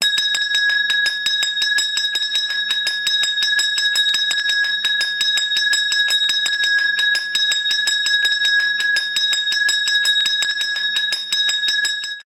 Звуки школьного колокольчика
Есть такой вариант, где звеним школьным колокольчиком в руке